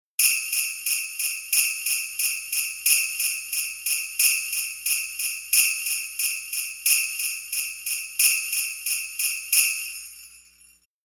Grelots